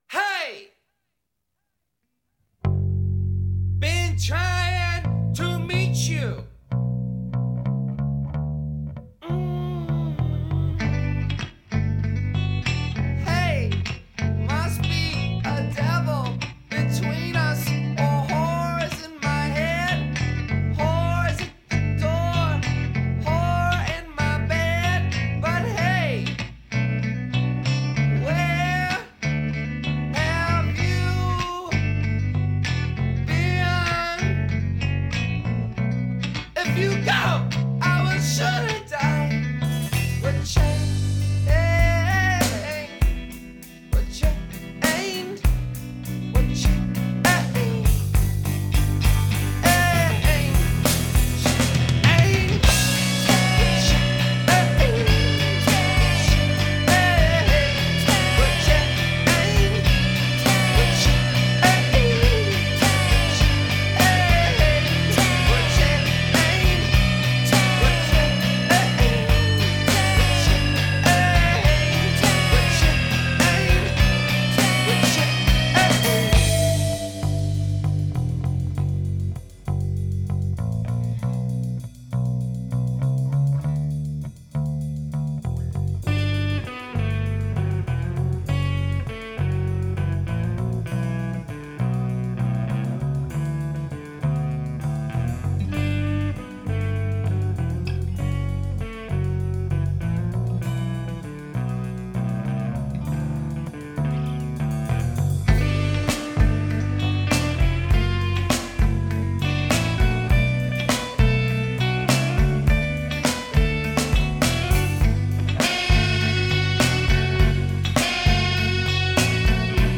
Género: Rock.